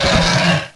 Heroes3_-_Azure_Dragon_-_HurtSound.ogg